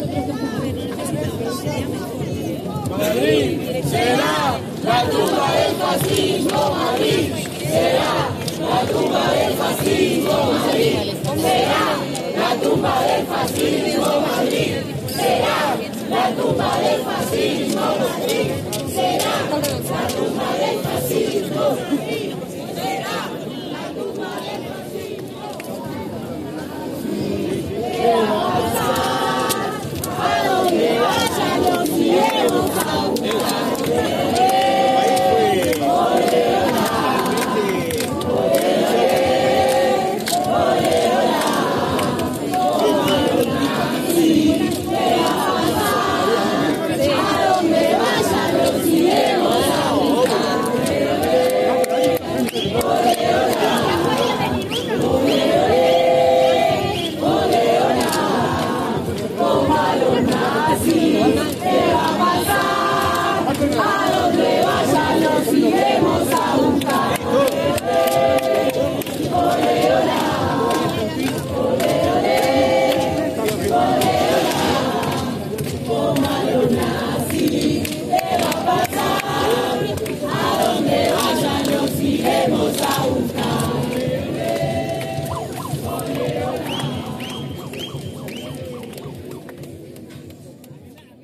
oa-espana-madrid-manifestacion-contra-milei-y-el-fascismo.mp3